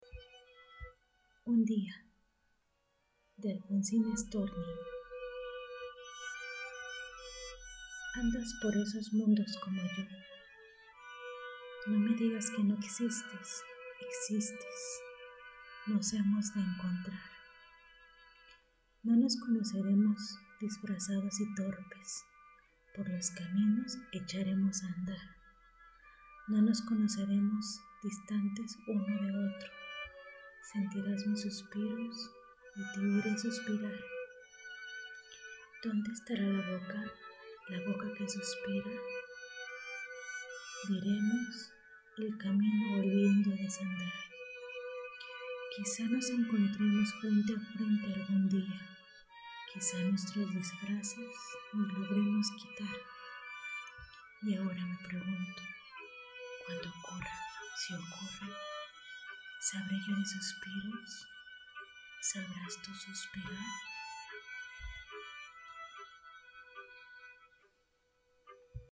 Kein Dialekt
Sprechprobe: Sonstiges (Muttersprache):
poemaalfonsinastorniundia.mp3